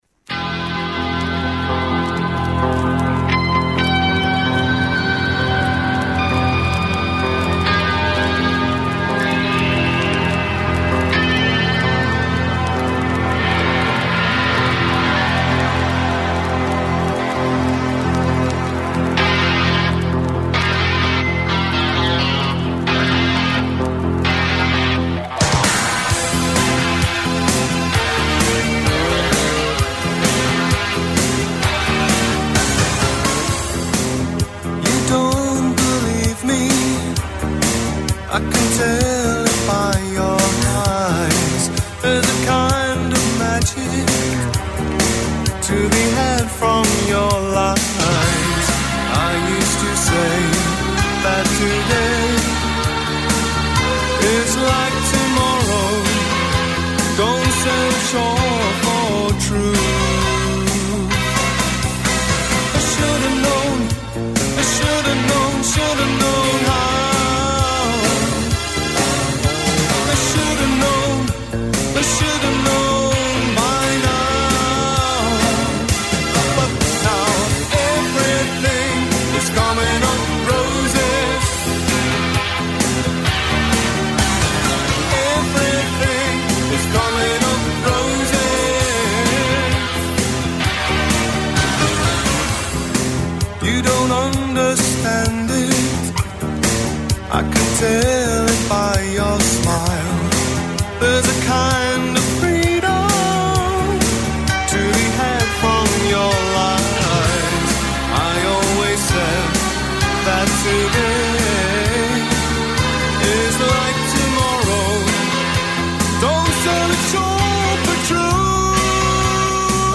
Ακούστε την εκπομπή του Νίκου Χατζηνικολάου στον ραδιοφωνικό σταθμό RealFm 97,8, την Πέμπτη 13 Μαρτίου 2025.